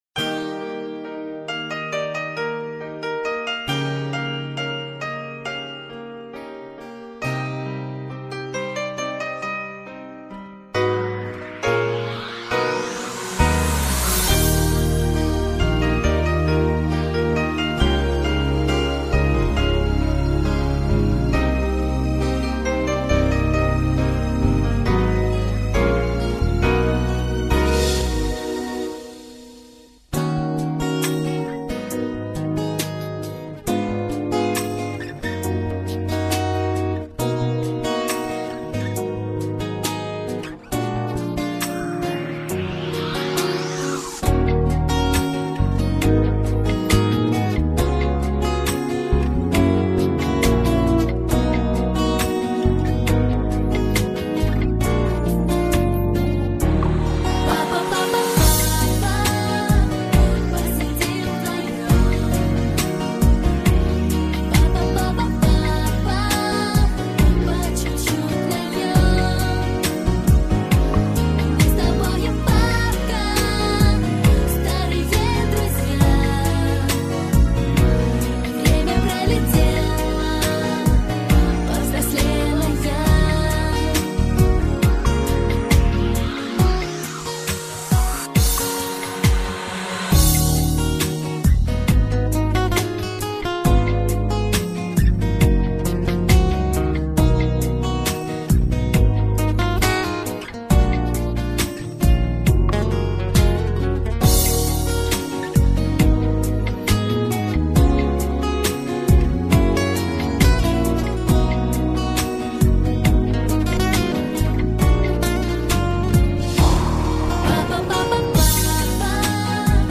Музыкальные минусовки